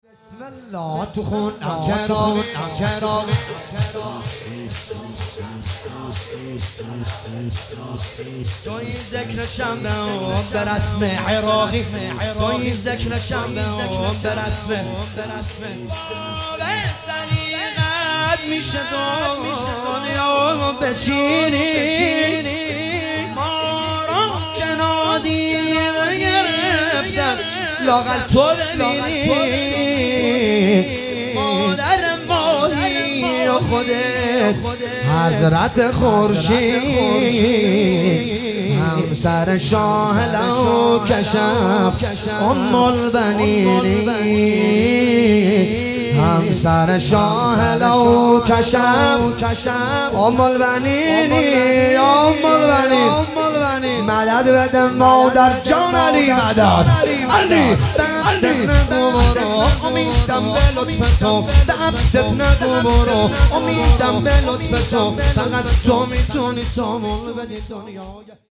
دانلود مداحی شور
فاطمیه اول
فاطمیه اول 1403